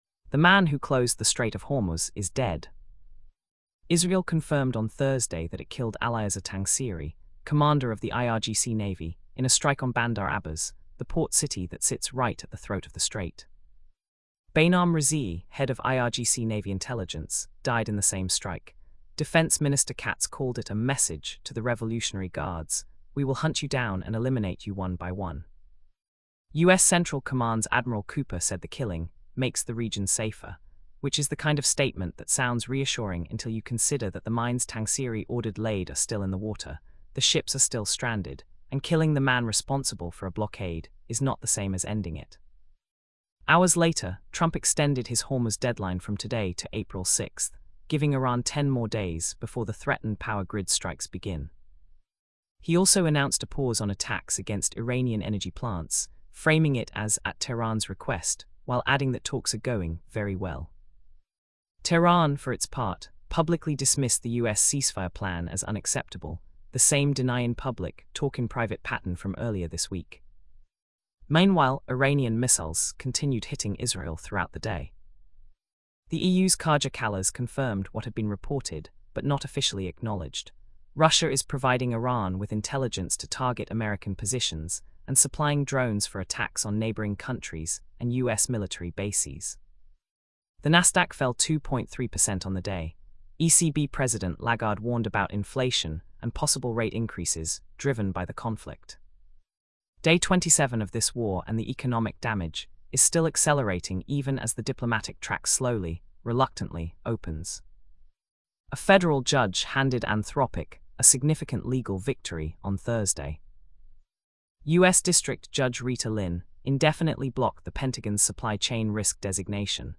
Listen to this briefing Download audio The man who closed the Strait of Hormuz is dead.